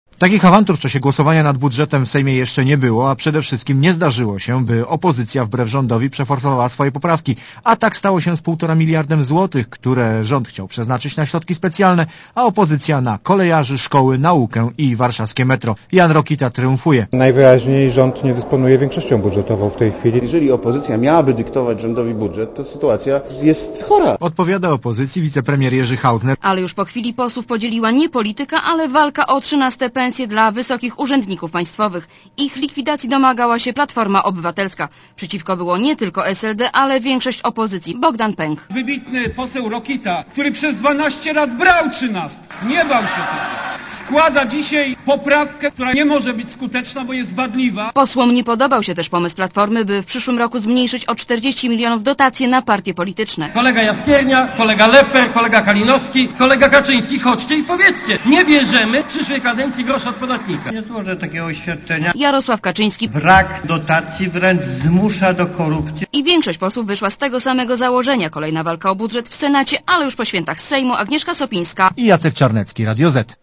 Komentarz audio (288Kb)